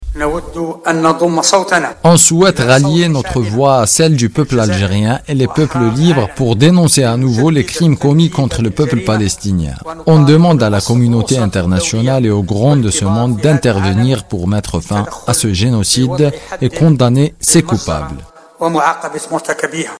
à la radio Chaine 3 Abdelkader Bensalah, président du Conseil de la nation, au micro de la radio Chaine 3